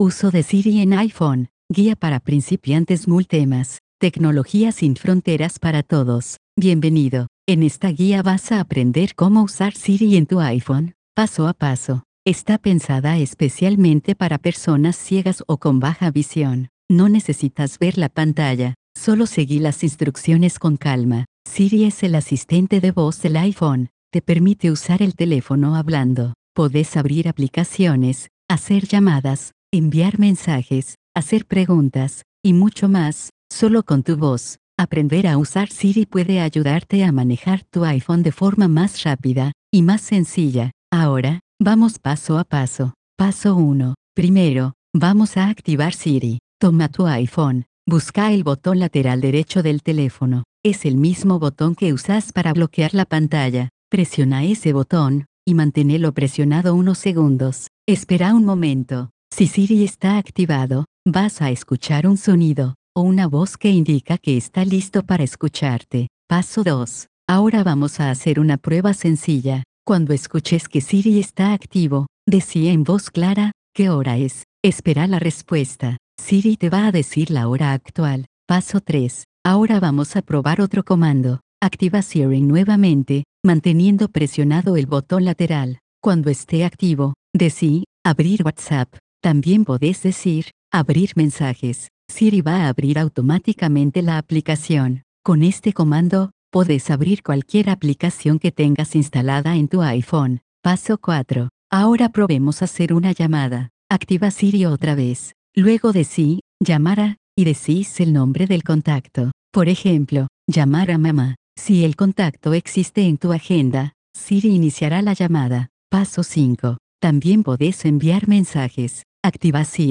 El anterior guion se generó en un formato de audio para que las personas ciegas o con baja visión puedan utilizarlo como guía auditiva.